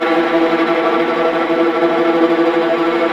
Index of /90_sSampleCDs/Roland L-CD702/VOL-1/STR_Vlas Bow FX/STR_Vas Tremolo